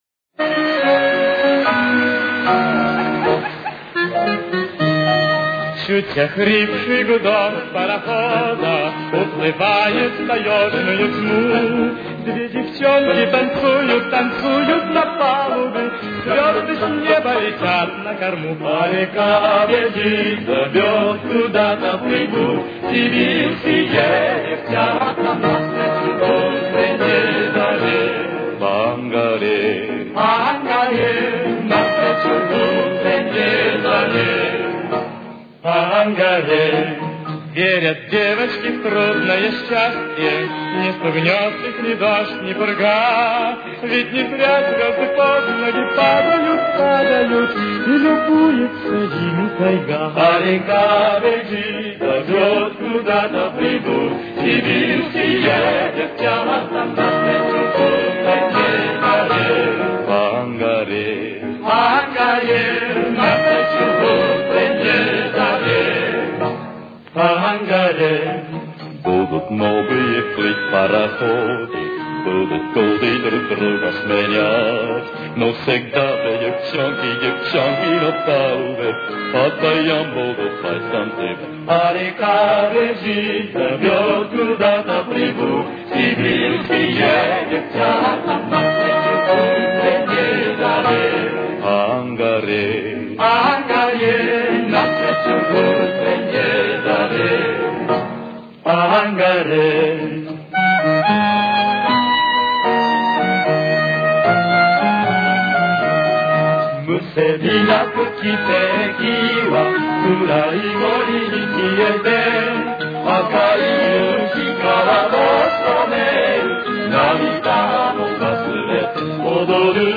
Тональность: Ми-бемоль минор. Темп: 233.